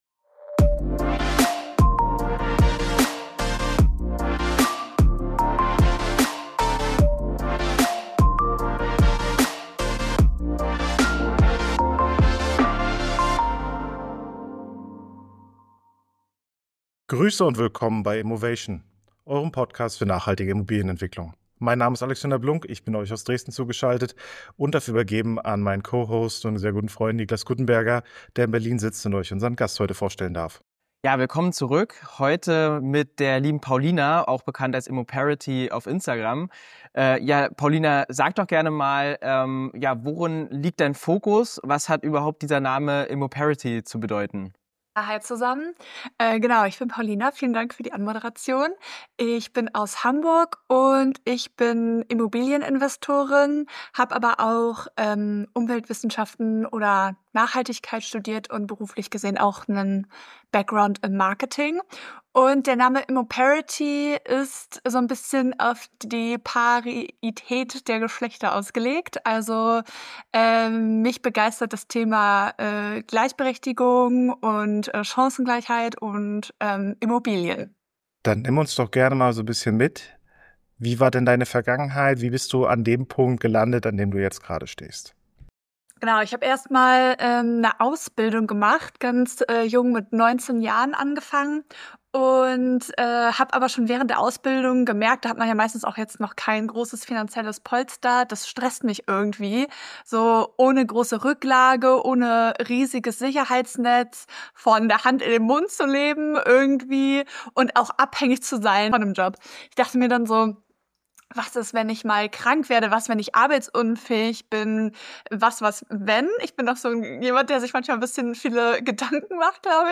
Wir sprechen über gesellschaftliche Rollenbilder, Mut, Purpose und was Cradle to Cradle mit Immobilien zu tun hat. Ein ehrliches, inspirierendes Gespräch über Selbstwirksamkeit, Scheitern, nachhaltigen Erfolg – und warum es manchmal nur eine Frage des Wollens ist.